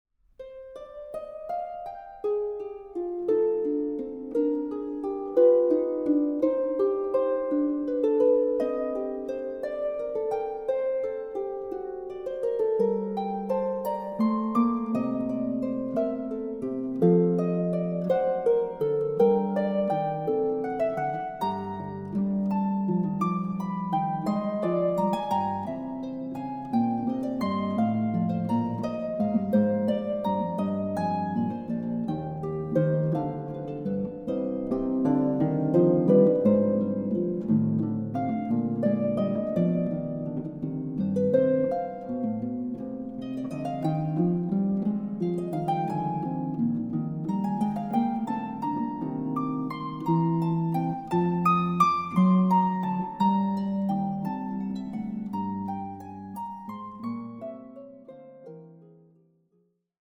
Harp
Recording: Festeburgkirche Frankfurt, 2024